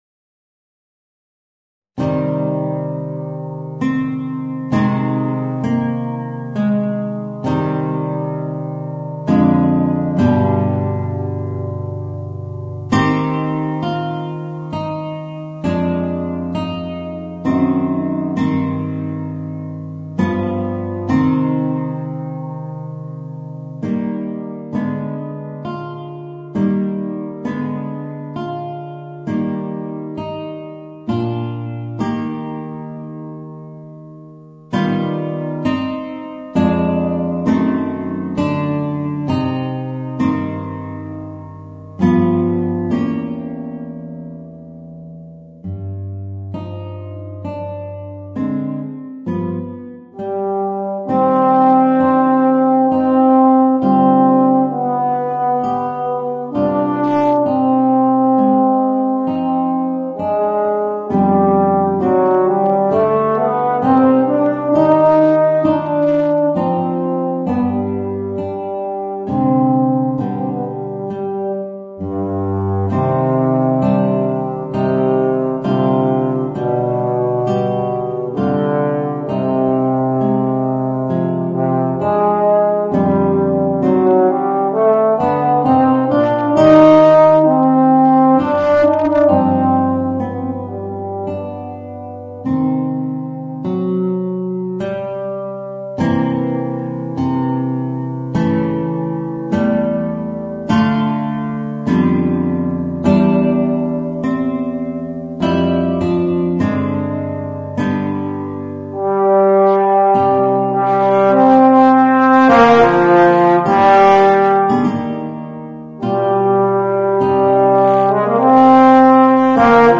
Voicing: Euphonium and Guitar